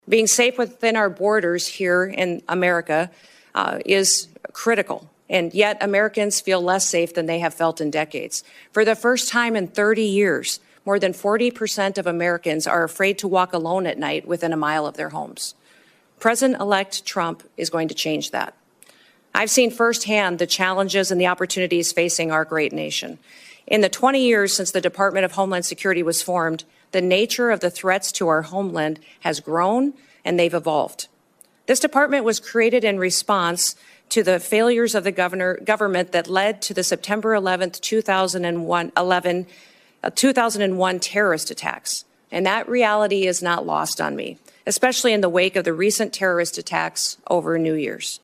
WASHINGTON, D.C.(DRGNews)- South Dakota Governor Kristi Noem answered questions from members of the United States Senate Homeland Security and Governmental Affairs Committee Friday during her confirmation hearing to become President-elect Donald Trump’s Secretary of Homeland Security.
In her opening statement, Noem said “securing our homeland is a serious, sacred trust that must be relentlessly pursued and can never be taken for granted.”